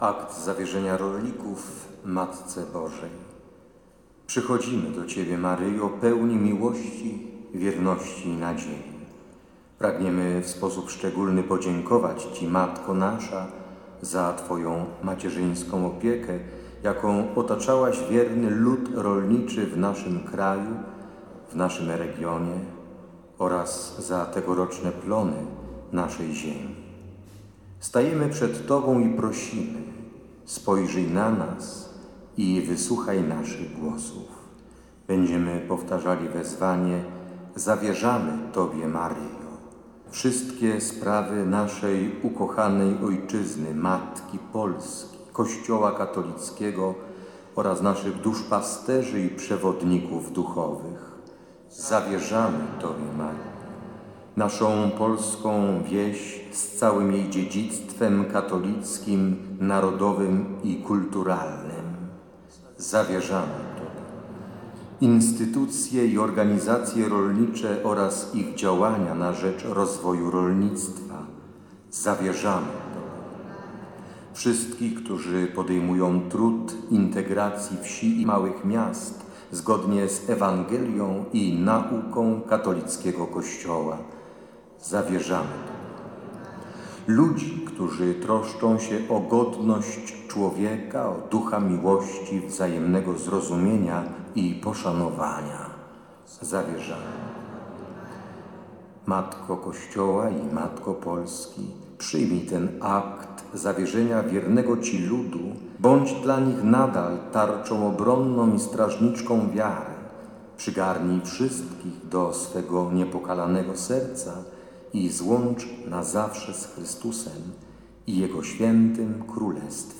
Po wyznaniu wiary odmówiono akt zawierzenia rolników Matce Bożej.
akt-zawierzenia.mp3